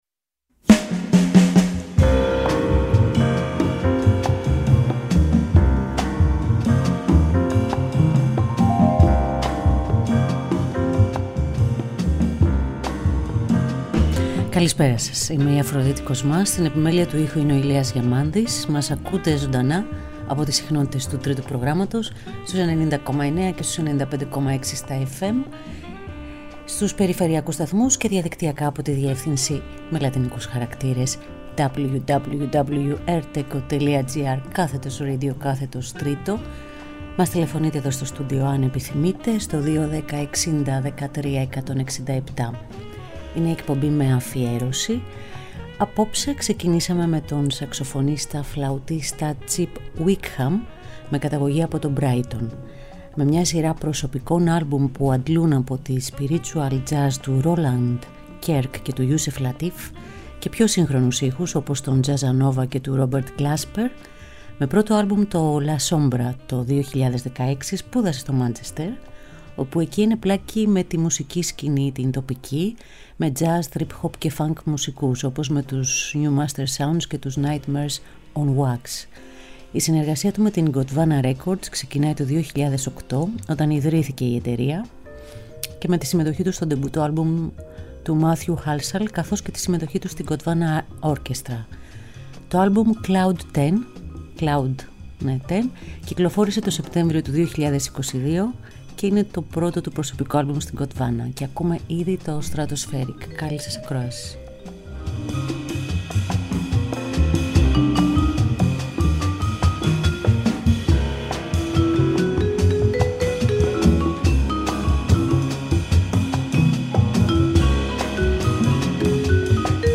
Κάθε Πέμπτη, ζωντανά από το στούντιο του Τρίτου Προγράμματος 90,9 & 95,6